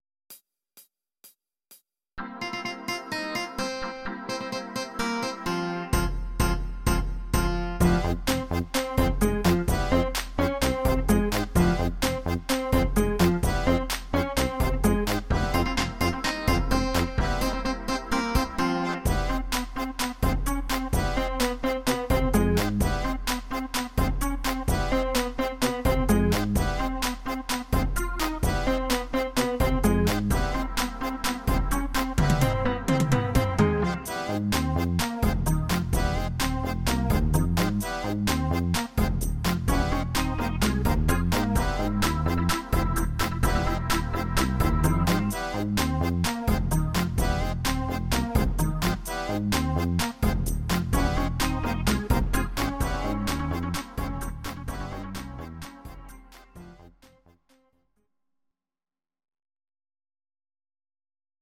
Audio Recordings based on Midi-files
German, 1990s